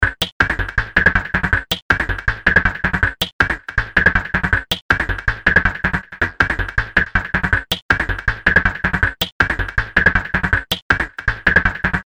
Pitter Patter.wav